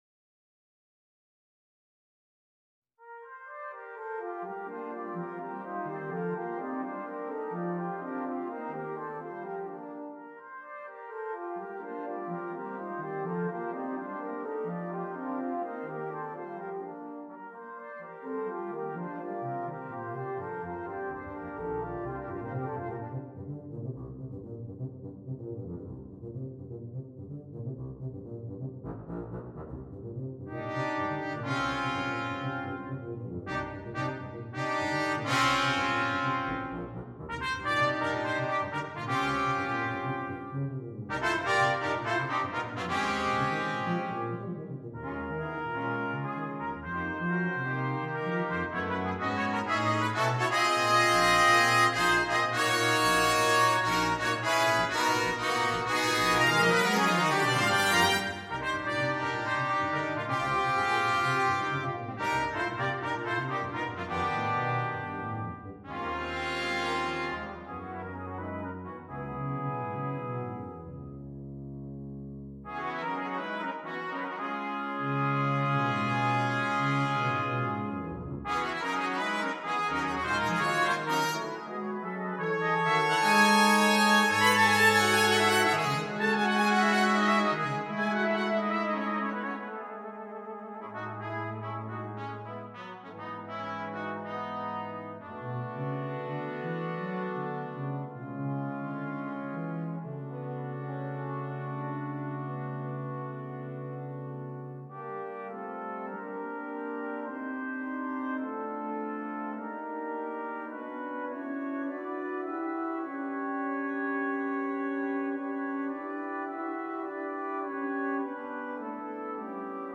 Besetzung: Brass Quintet